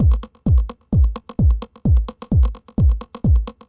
1 channel
tzwdrum1.mp3